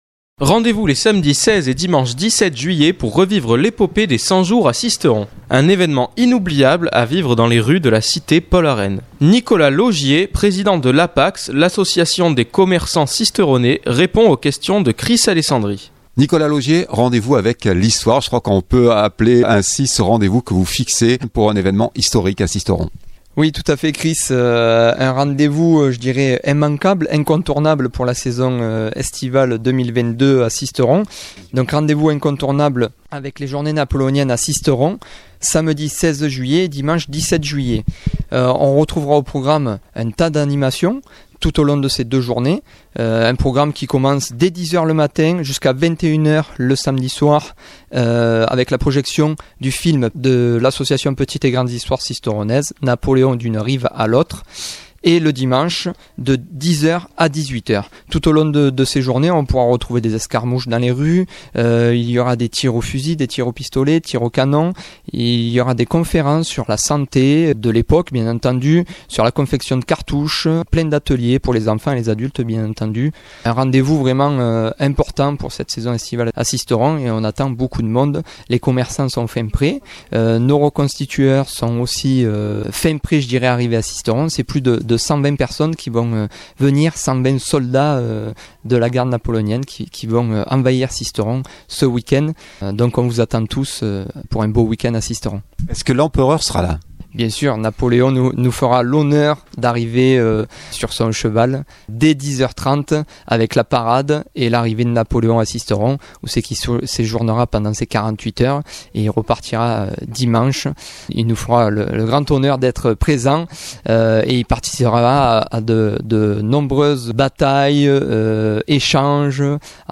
répond aux questions